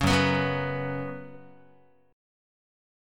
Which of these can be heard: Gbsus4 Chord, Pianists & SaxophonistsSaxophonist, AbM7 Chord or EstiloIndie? Gbsus4 Chord